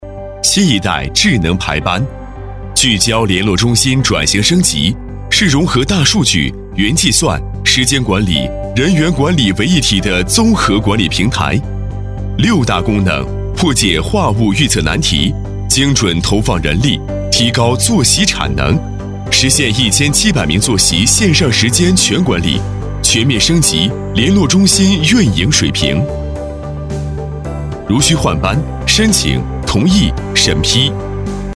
科技感男240号
轻松自然 电子科技宣传片配音
年轻磁性男音，擅长科技感宣传片，专题汇报、旁白，人物讲述等题材。